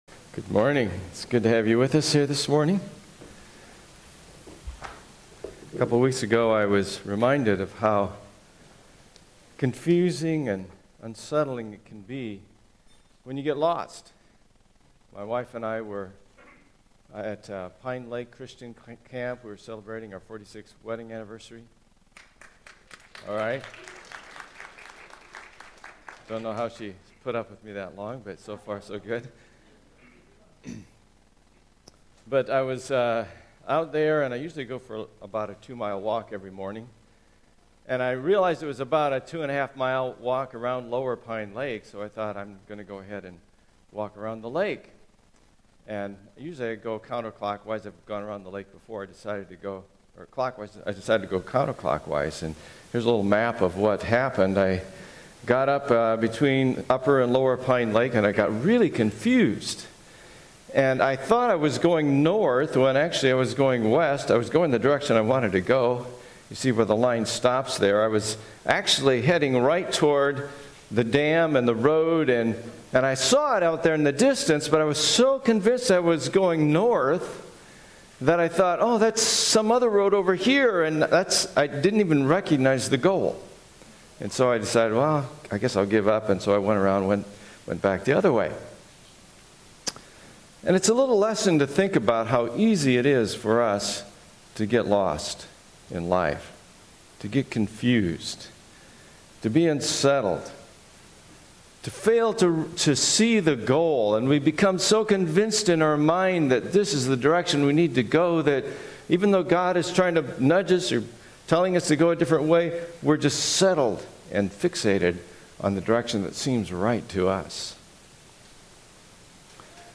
2021 Stay up to date with “ Stonebrook Church Sermons Podcast ”